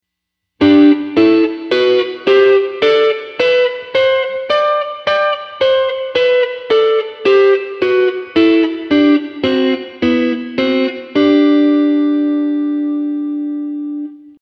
4度音程のダブルストップ2弦、3弦